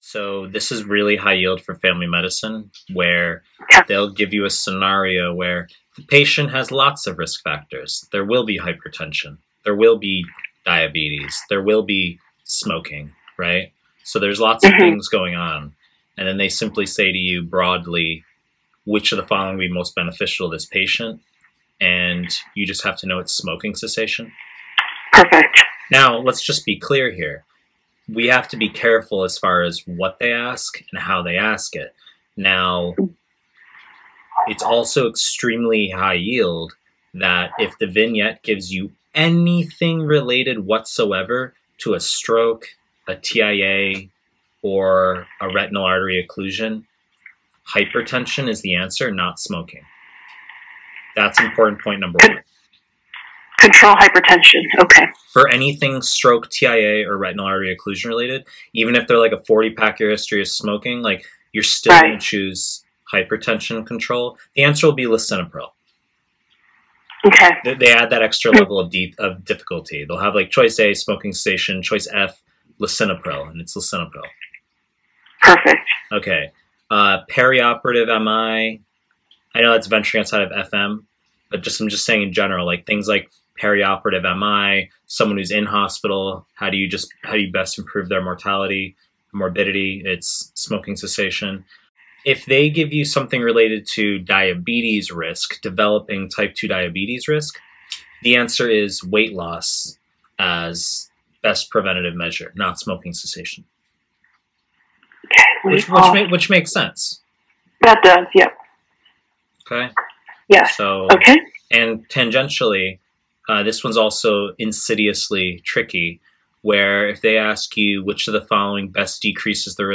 Family medicine / Pre-recorded lectures